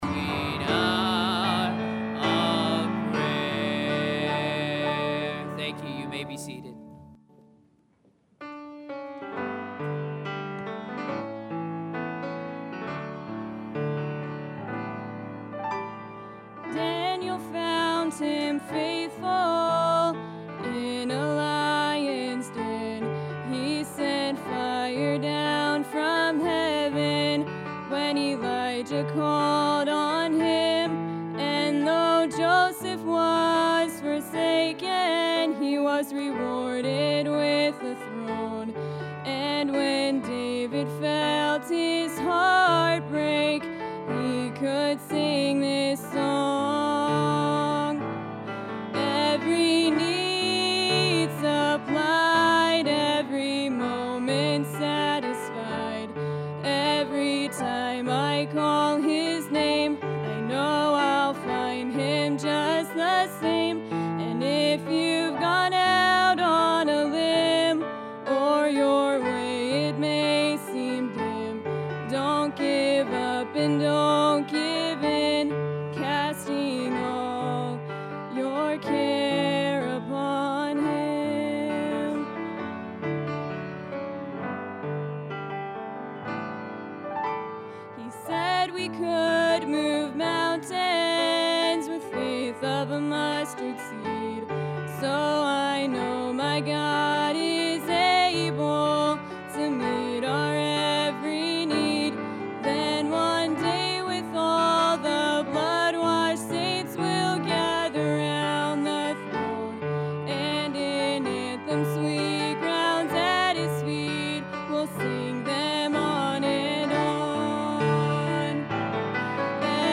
Sunday Evening Service – Shasta Baptist Church